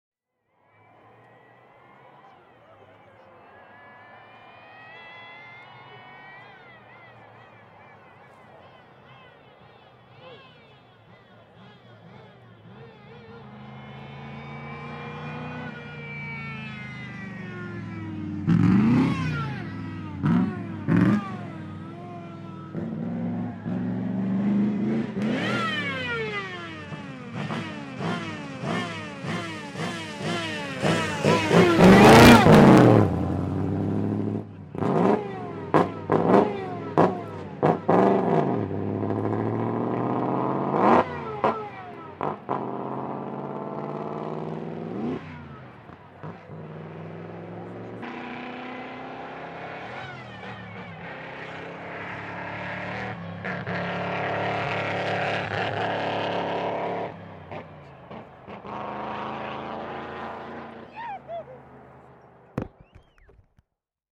Mercedes-Benz 280 SE (1977) - Starten und Leerlauf